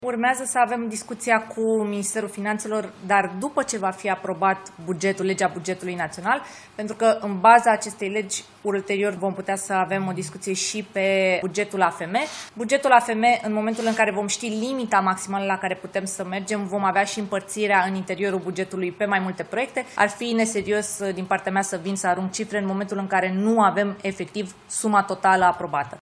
Ministra Mediului, Diana Buzoianu: „Vom avea și împărțirea în interiorul bugetului pe mai multe proiecte”